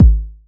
cch_kick_low_punch_kaoz.wav